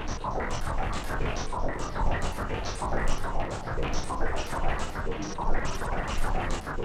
STK_MovingNoiseA-140_01.wav